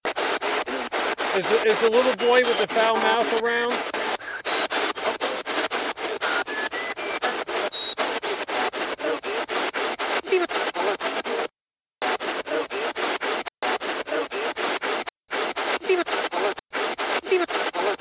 When you get to the 10 second mark,  you hear what sounds like a little boy replying "Here"!
It is a male voice saying  "I'm here."    It's clear,  but much fainter than the little boy responding to us!    Both sets of voices replayed twice at the end of the clip.